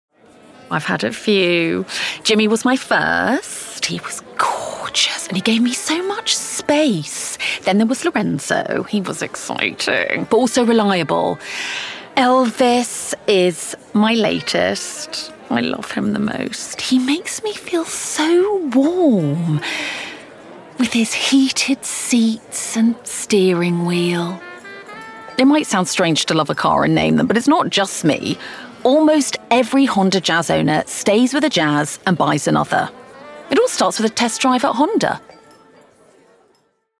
RP
Female
Assured
Bright
Dry
HONDA COMMERCIAL